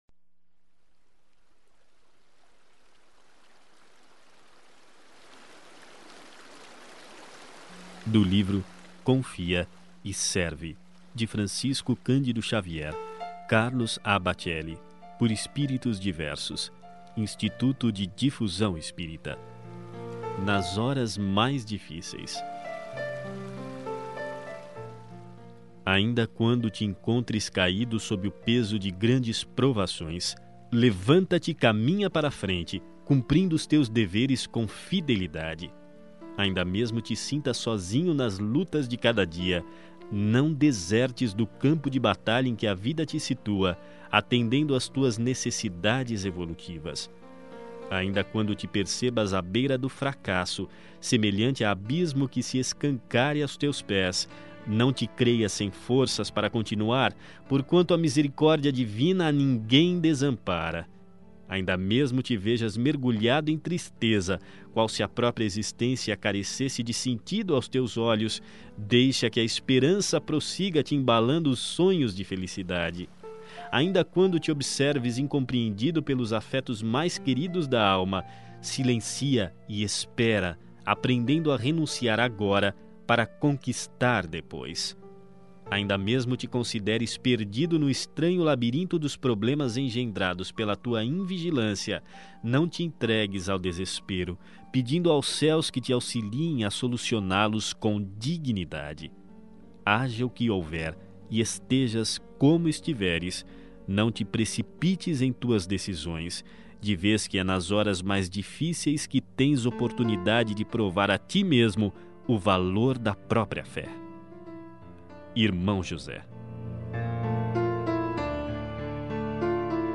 Ouça outras mensagens na voz de Chico Xavier Clicando aqui